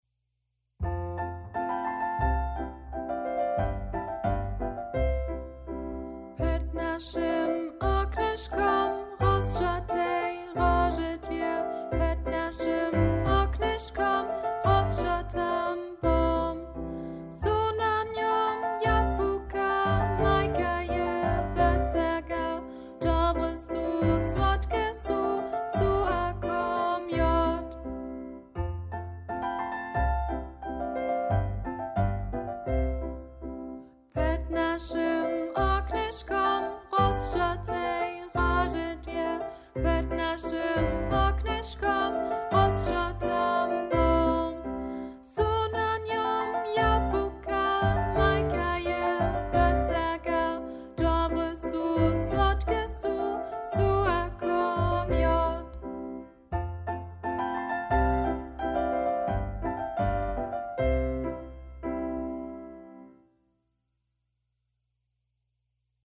melodija: česki ludowy spiw